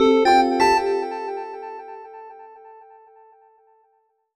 jingle_chime_02_positive.wav